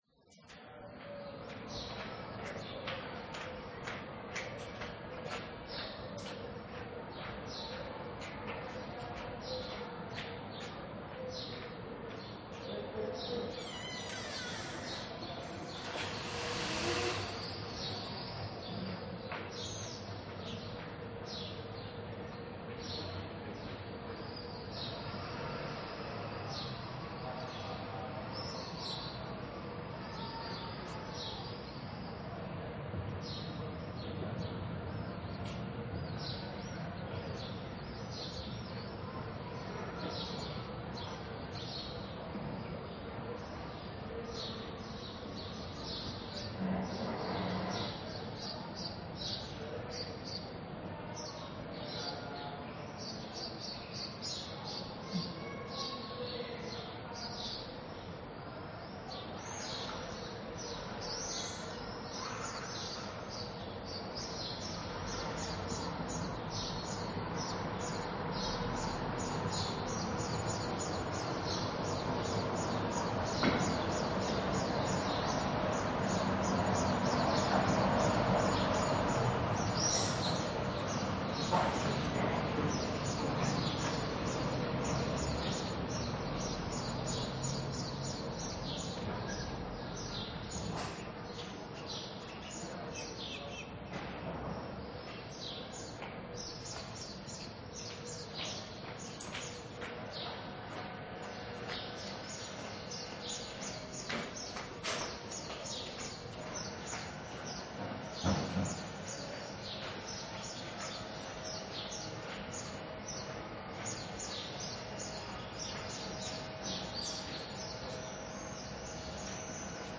AMBIENTE DE CALLE ESTRECHA CON PASO DE UN VEHICULO GRATIS 1
Ambient sound effects
ambiente_de_calle_estrecha_con_paso_de_un_vehiculo_gratis_1.mp3